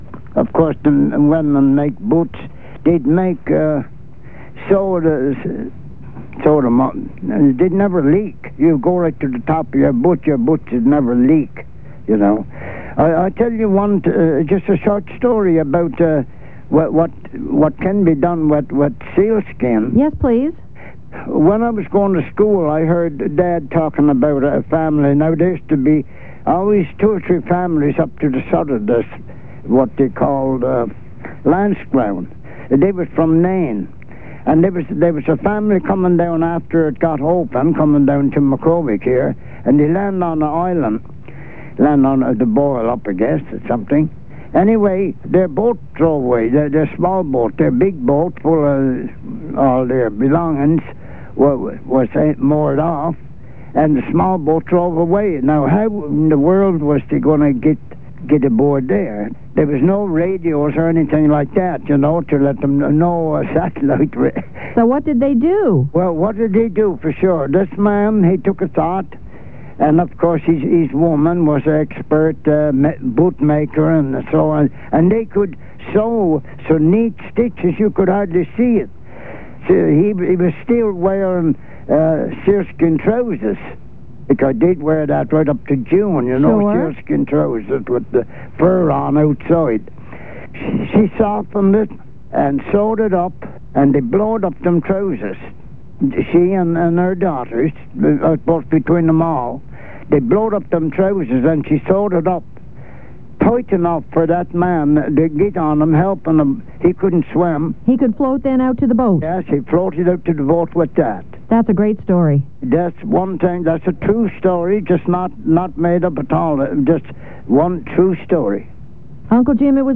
Dialects of English: Irish English volume 1 - The North of Ireland
Northwest coast